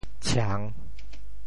How to say the words 唱 in Teochew？
唱 Radical and Phonetic Radical 口 Total Number of Strokes 11 Number of Strokes 8 Mandarin Reading chàng TeoChew Phonetic TeoThew ciê3 白 ciang3 文 Chinese Definitions 唱〈动〉 (形声。
tshiang3.mp3